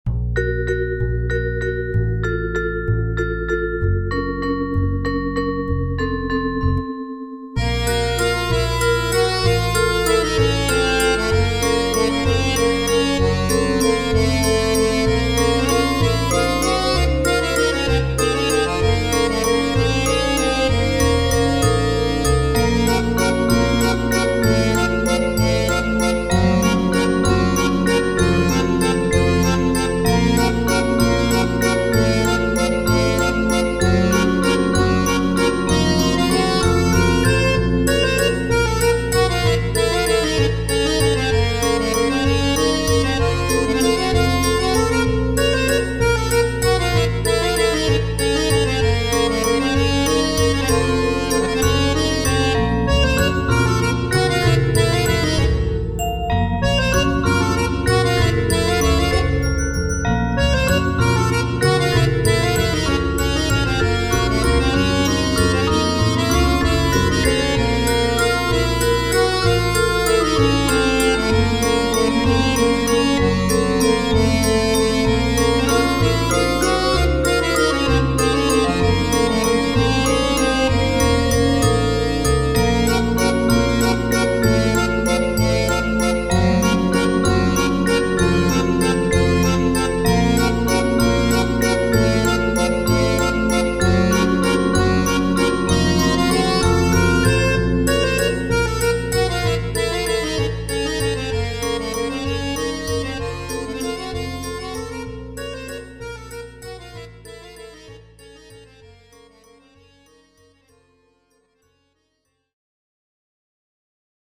タグ: ハロウィン 不思議/ミステリアス 不気味/奇妙 怪しい コメント: ハロウィン×バル・ミュゼット風の楽曲。